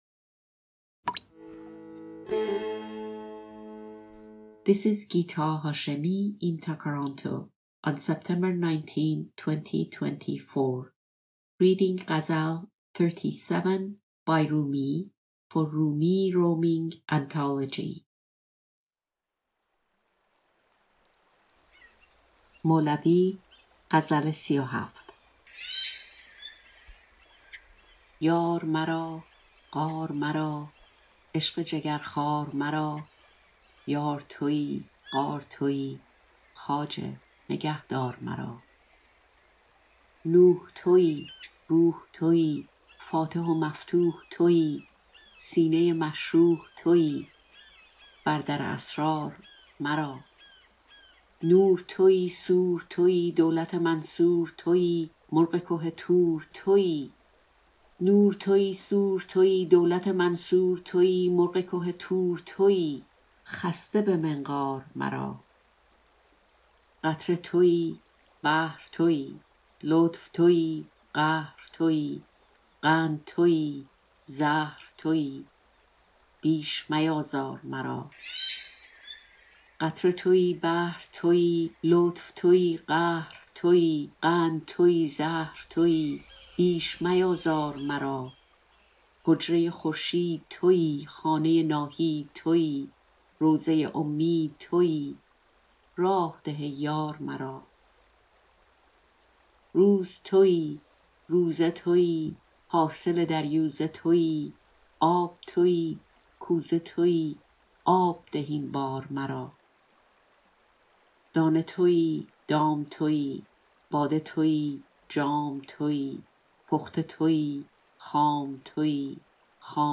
Rumi, Ghazal 37, Translation, Rumi roaming, Poetry, Love